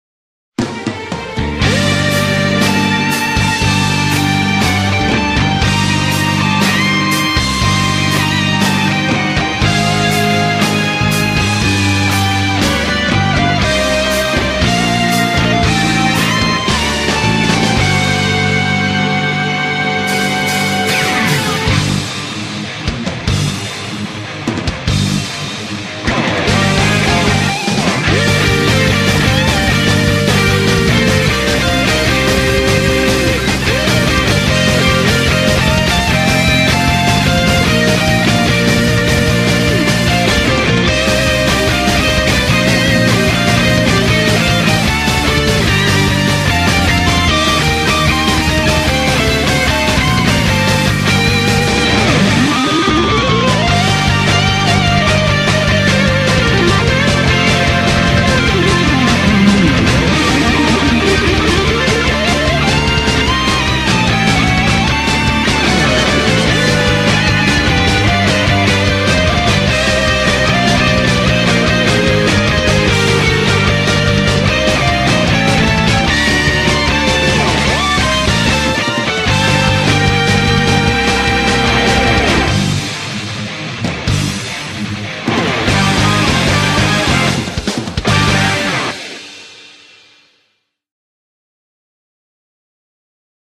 BPM104-150
Audio QualityPerfect (Low Quality)